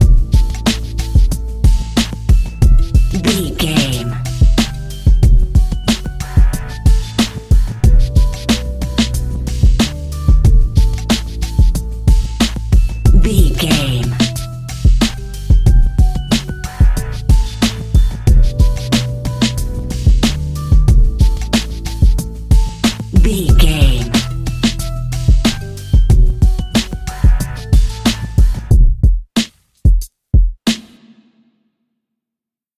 Aeolian/Minor
drum machine
synthesiser
electric piano
funky